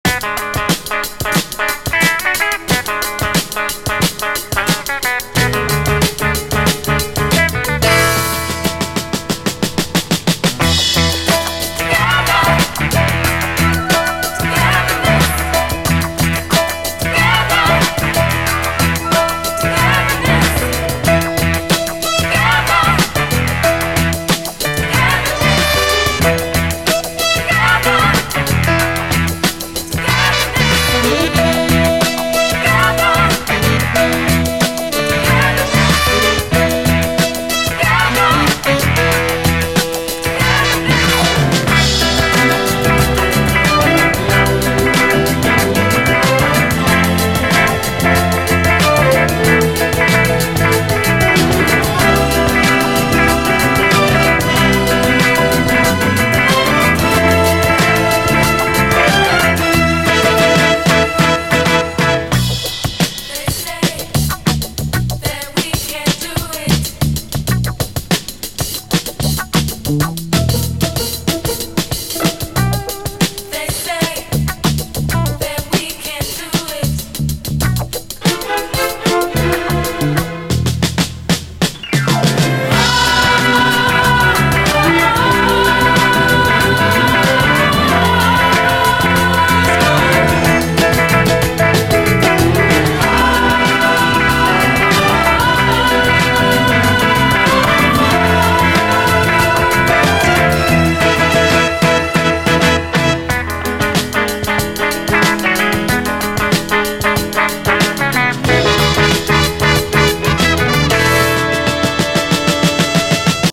SOUL, JAZZ FUNK / SOUL JAZZ, 70's～ SOUL, JAZZ, 7INCH
エレガンスとファンクネスが同居する最高ビューティフル・レアグルーヴ！
中盤にブレイクも搭載！